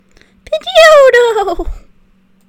Cries
PIDGEOTTO.mp3